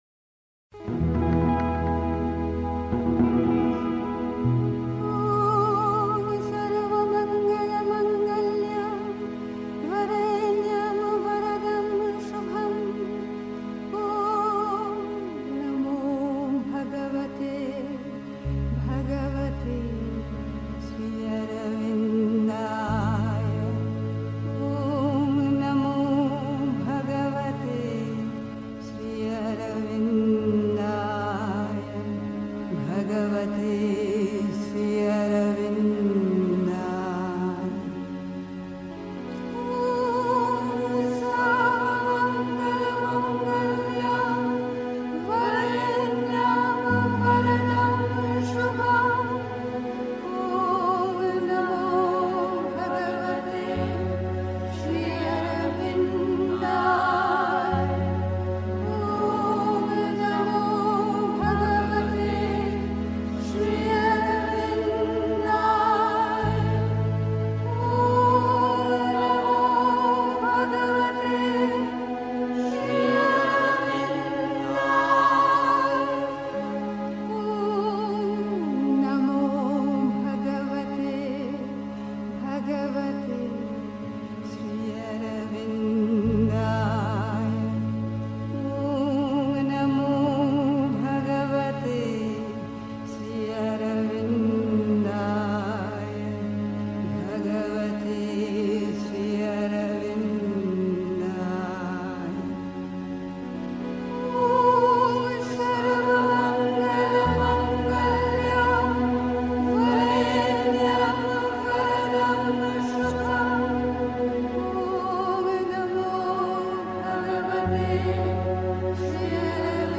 Die bestmögliche Zusammenarbeit mit dem göttlichen Werk (Die Mutter, CWM Vol 8, p. 250) 3. Zwölf Minuten Stille.